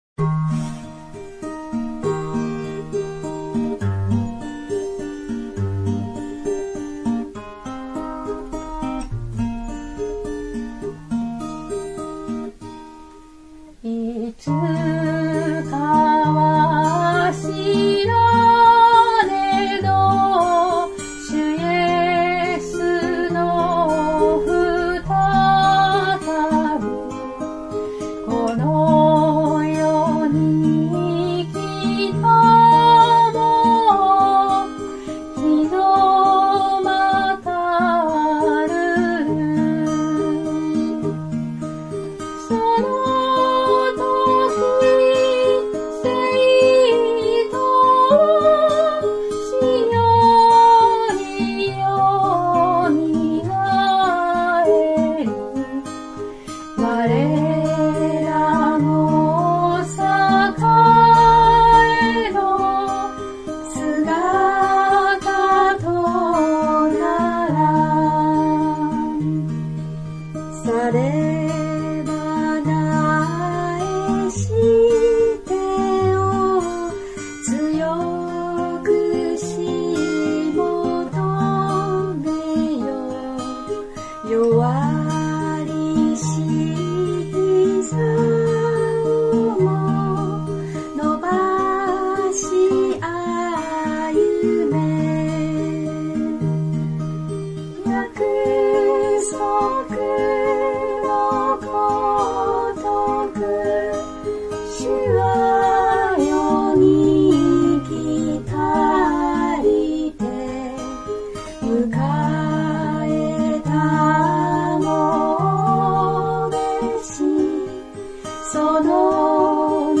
唄